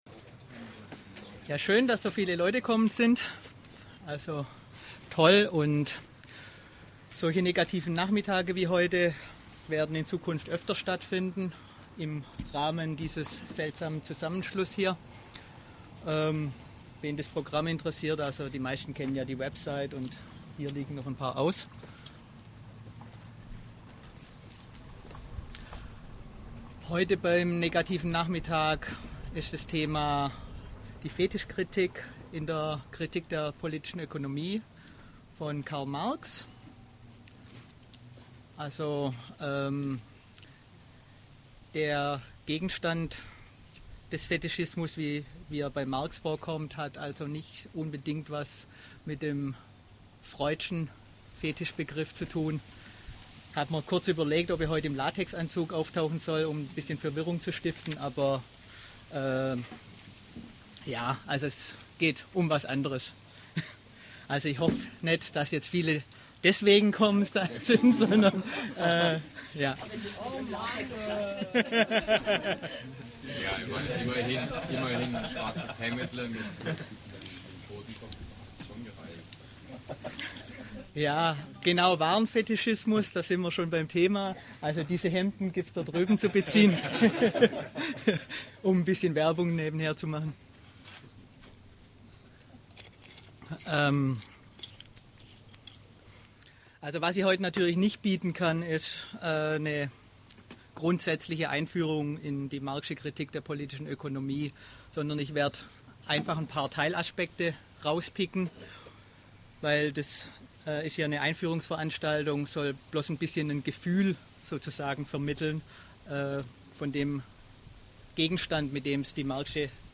Teil 1 (Zur Wortherkunft und zur Analogiebildung in der polit-ökonomischen Kritik, Aspekte des Fetischismus) des Vortrags